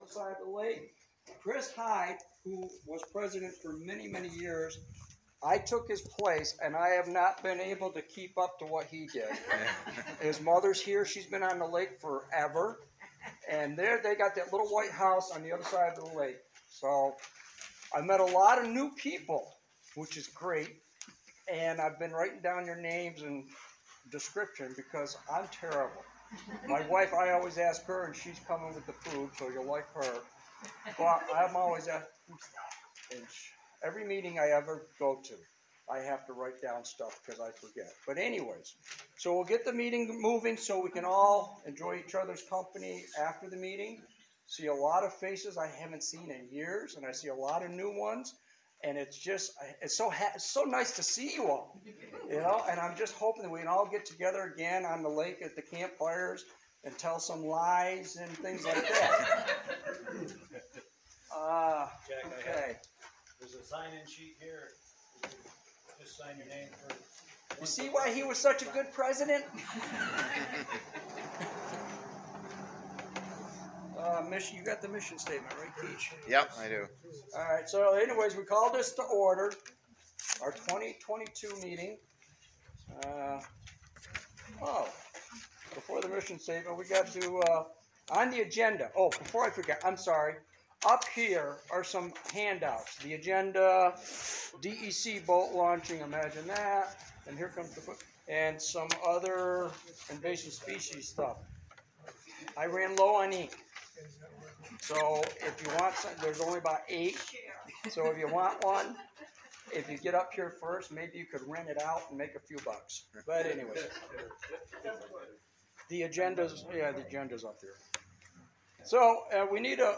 2023-07-08 Annual Meeting- Agenda.